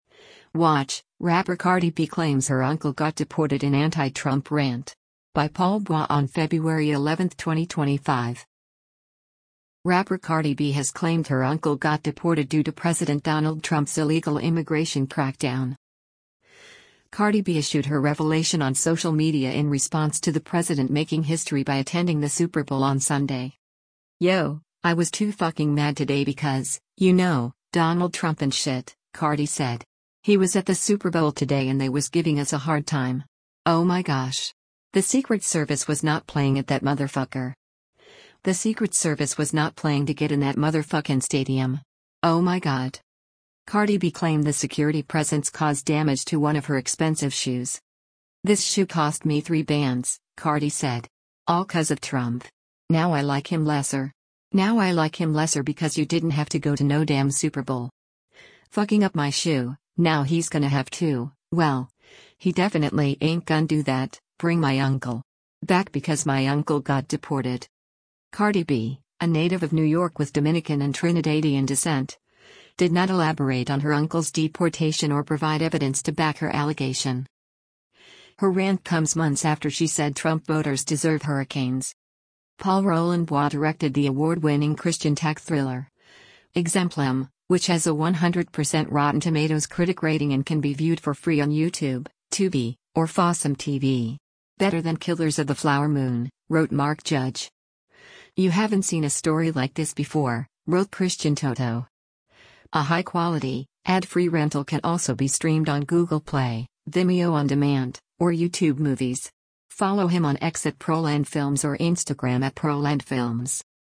Cardi B issued her revelation on social media in response to the president making history by attending the Super Bowl on Sunday.